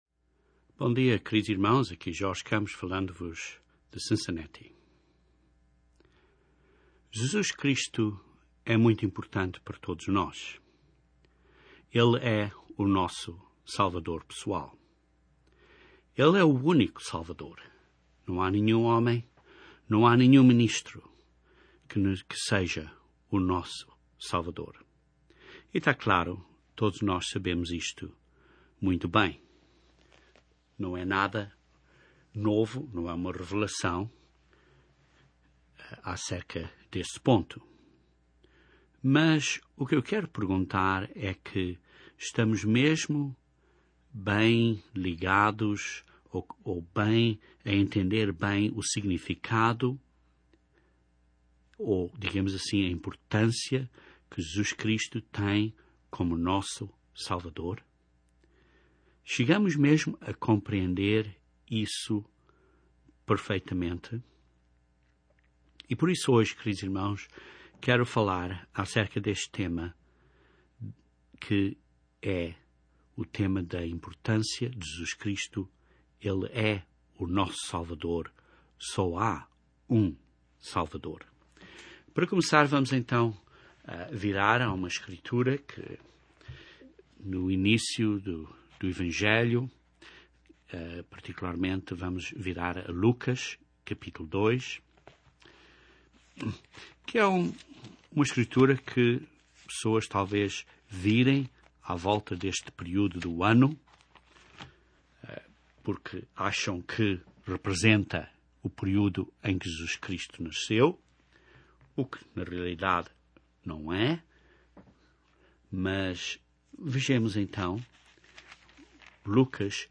Este sermão aborda este assunto e demonstra como Jesus é o nosso Salvador pessoal, assim como é o Salvador da Igreja!